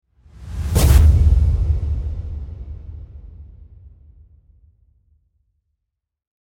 Classic Cinematic Rise And Hit Sound Effect
A powerful audio build-up that rises with intensity and climaxes in a dramatic hit, perfect for trailers, films, games, or video transitions. This epic rise and impact sound adds suspense, energy, and cinematic tension to your projects.
Classic-cinematic-rise-and-hit-sound-effect.mp3